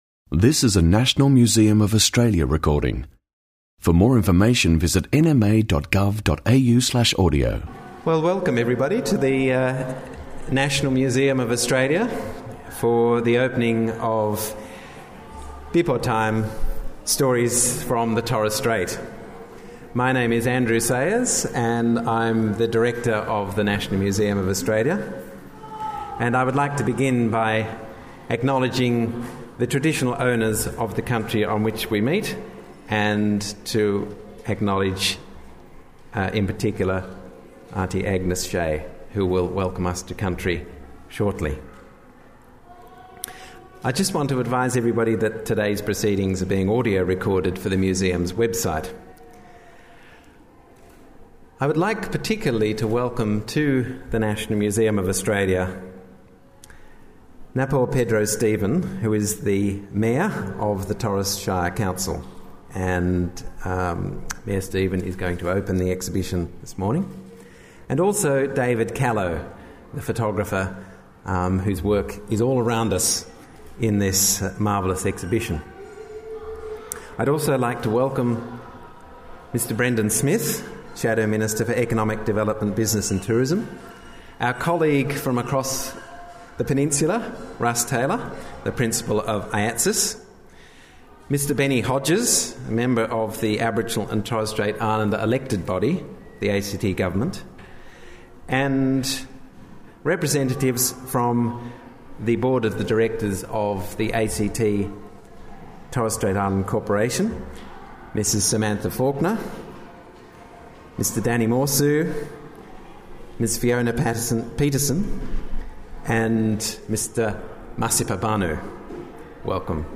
Exhibition launch: Bipotaim: Stories from the Torres Strait | National Museum of Australia
Pedro Stephen, Mayor of the Torres Shire Council, opens the exhibition of photographs by David Callow which is complemented by objects from the National Museum’s collections that share stories about the lives, culture and identity of Torres Strait Islanders.